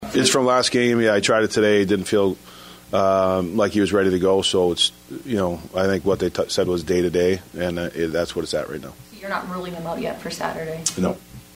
The Wild were without Kirill Kaprizov who missed his first game of the season with a lower body injury. Head coach John Hynes on the injury to Kaprizov.